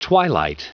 Prononciation du mot twilight en anglais (fichier audio)
Prononciation du mot : twilight